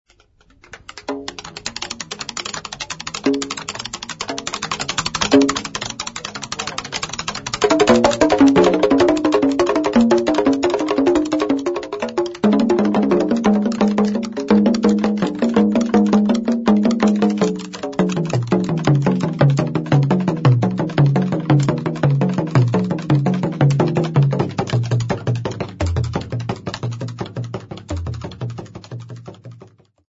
Entenga za Kabaka (Performer)
Folk drum tune
Royal drummers
Drums
Kampala
Drum tunes by Entenga Royal dummers with a set of 15 tuned conical laced drums, laced, closed, single
Hugh Tracey (Recorded by)